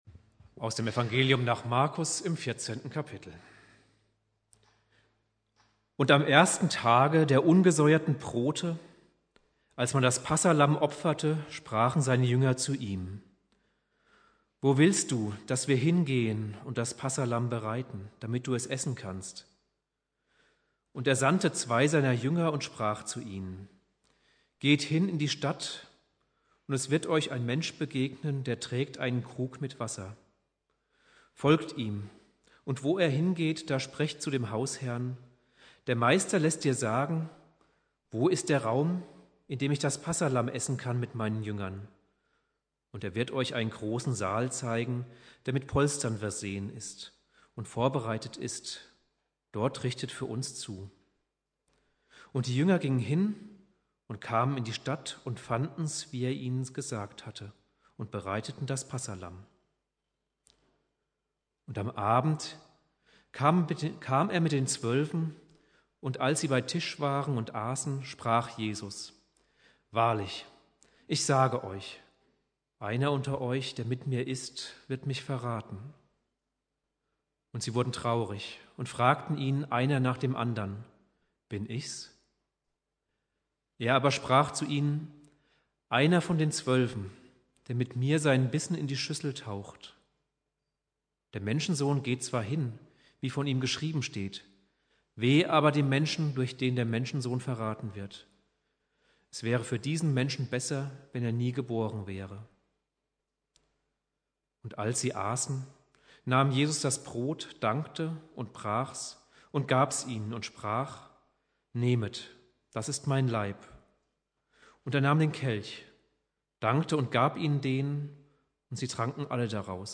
Predigt
Gründonnerstag Prediger